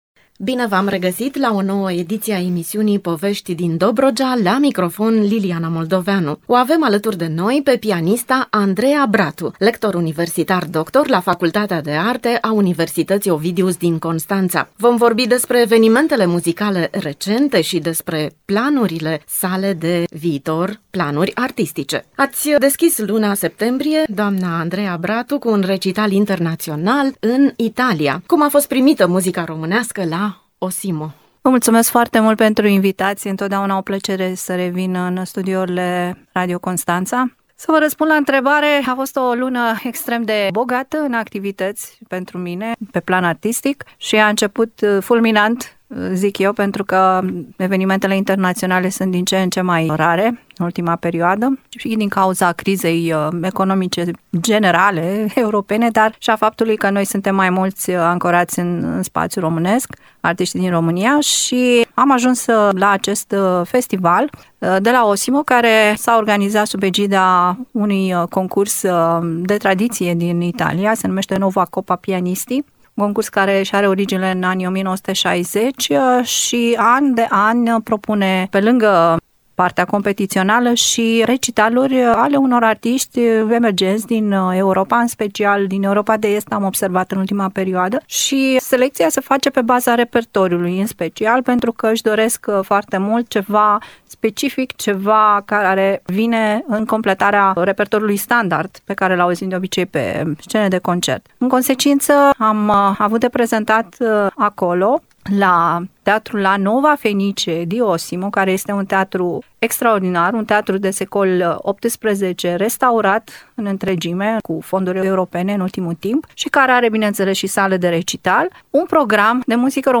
Un dialog